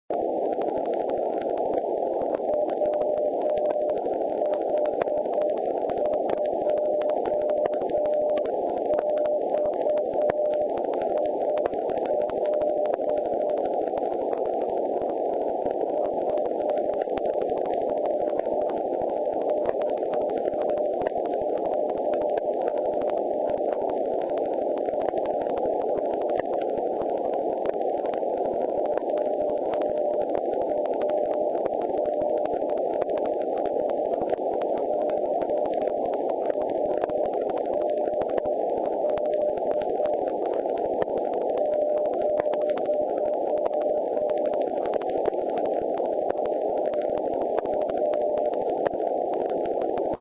In and out of the noise here ... short .mp3 at
> NE-SW. Running abt 80W CW on 460.1